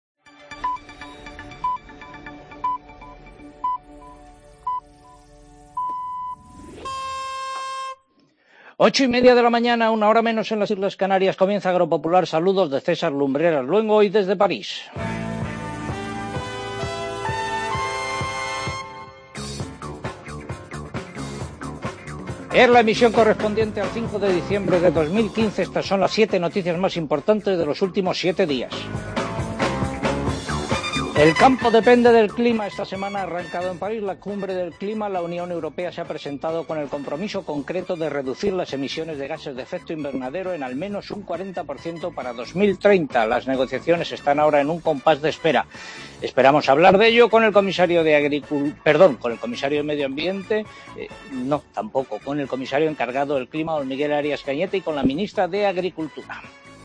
Agropopular desde París por la Cumbre del Clima: Así ha sonado el inicio del programa